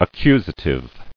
[ac·cu·sa·tive]